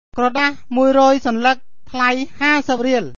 krawdaH moo-uhy roay sawnluhk th*la-ee hâseup ree-uhl